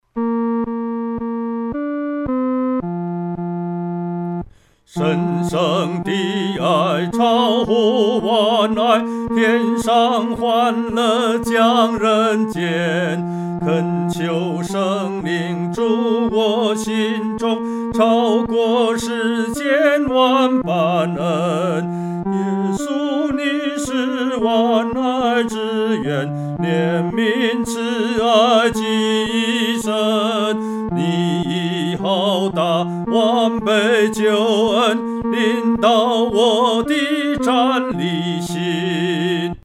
独唱（第三声）